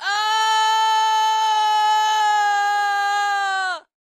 scream4.ogg